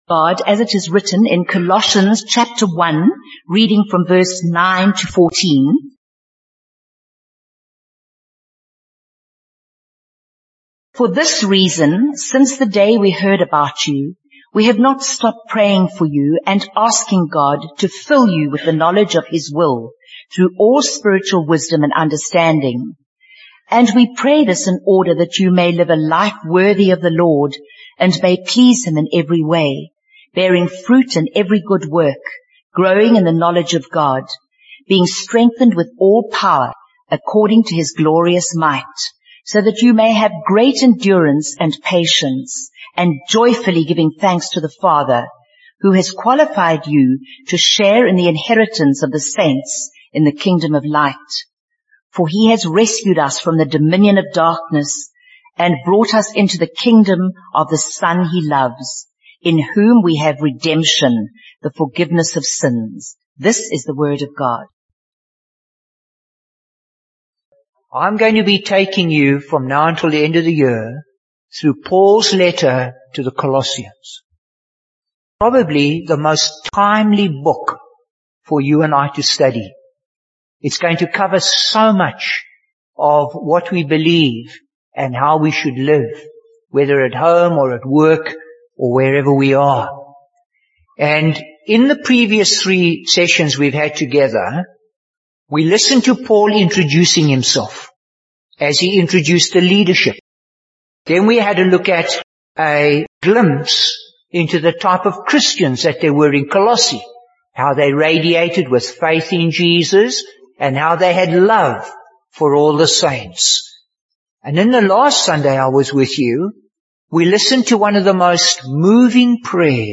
Bible Text: Colossians 1: 9-14 | Preacher: Bishop Warwick Cole-Edwards | Series: Colossians